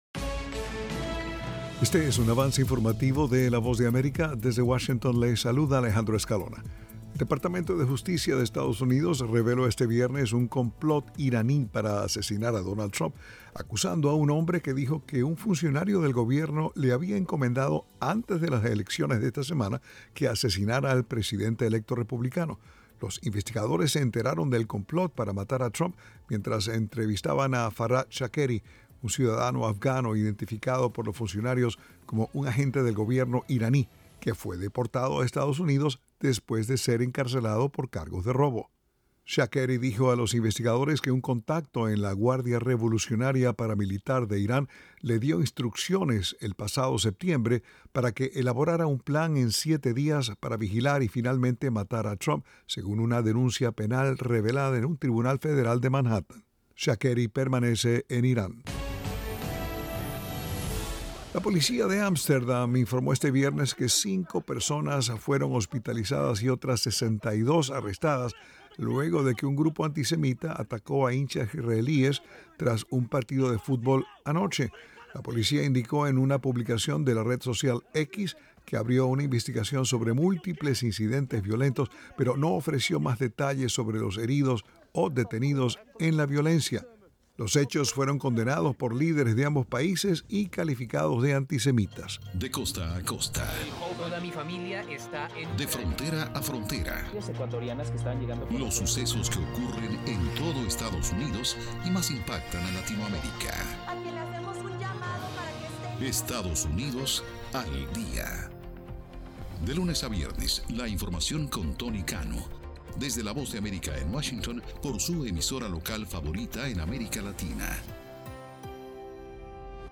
El siguiente es un avance informativo presentado por la Voz de América desde Washington.